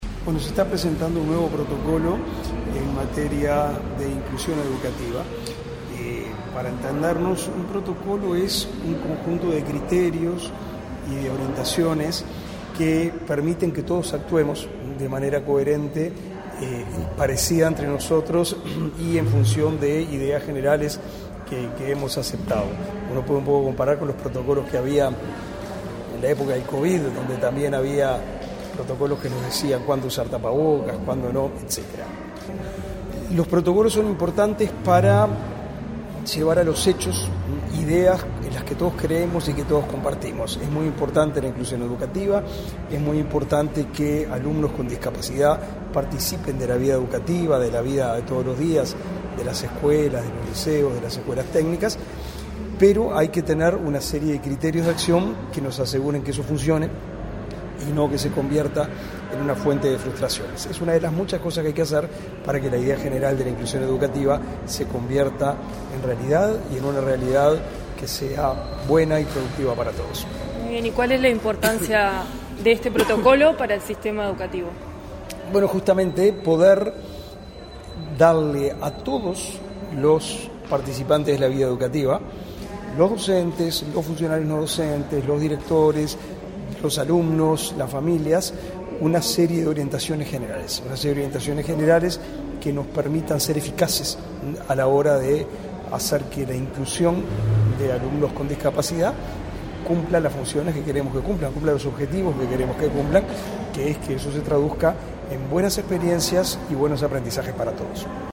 Entrevista al ministro del MEC, Pablo da Silveira
Tras participar en el acto de presentación del Protocolo de Actuación para Garantizar el Derecho a la Educación Inclusiva de las Personas con